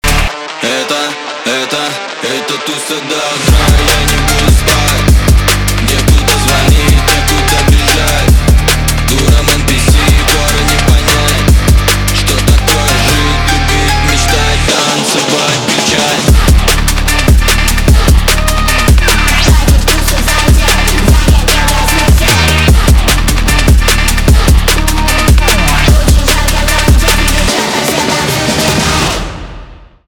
edm
электроника
битовые , басы , качающие , жесткие